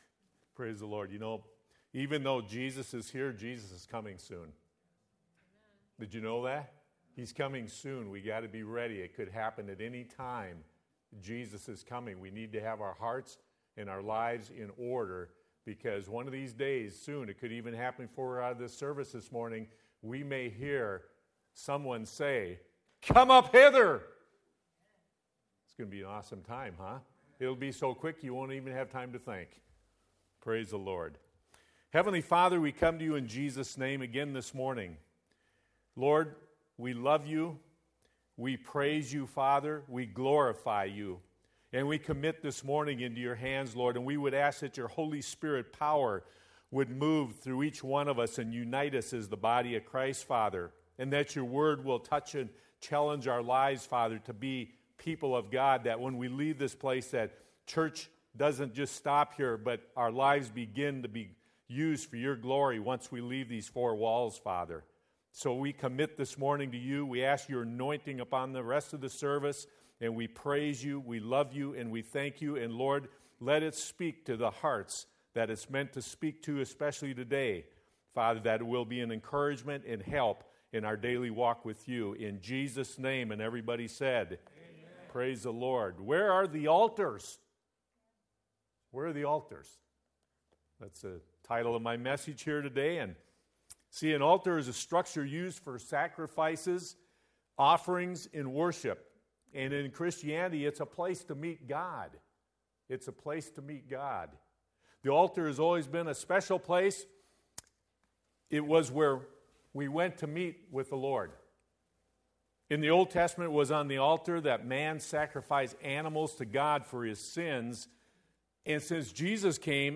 Sermons Archive - Page 55 of 61 - Crossfire Assembly